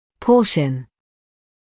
[‘pɔːʃn][‘поːшн]часть, доля; сегмент; участок